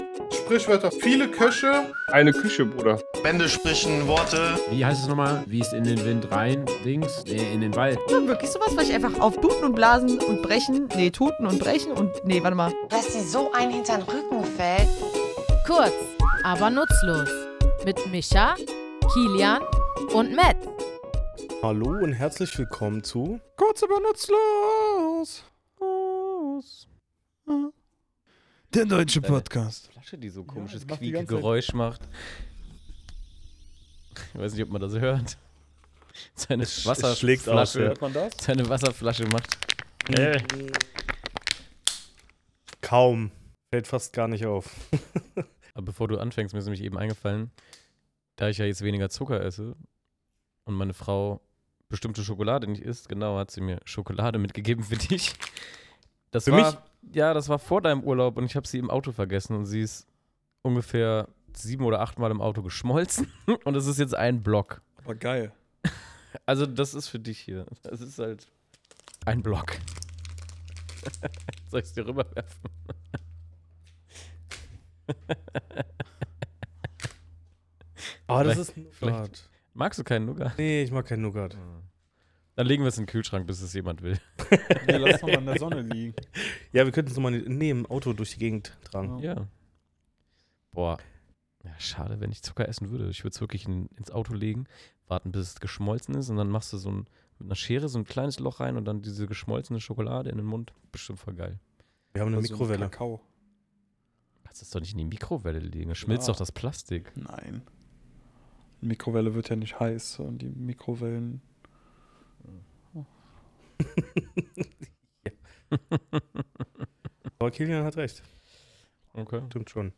Was bedeutet es, wenn ein Geheimnis längst keines mehr ist – und warum sind es ausgerechnet Spatzen, die als Boten auftreten? Wir, drei tätowierende Sprachliebhaber, schnacken in unserem Tattoostudio über die Herkunft dieser Redensart, ihre biblischen Bezüge und wie sie bis heute in unserem Sprachgebrauch weiterlebt.